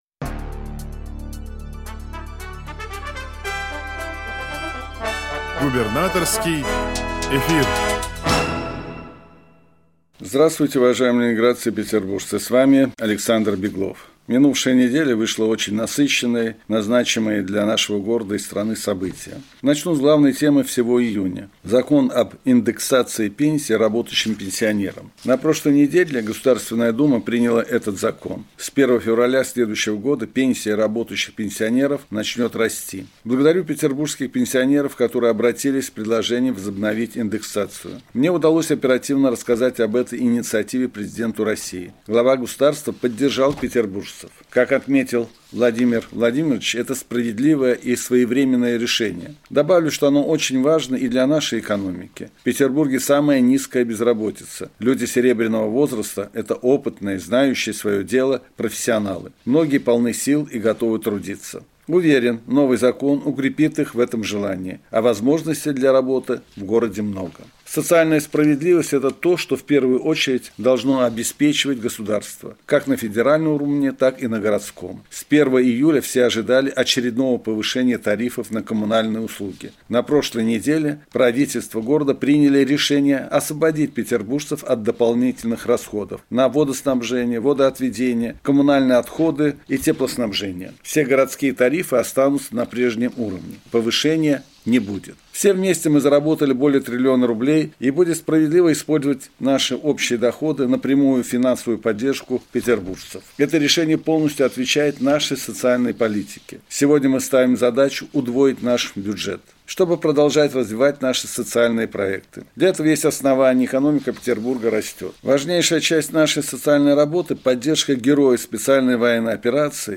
Радиообращение – 1 июля 2024 года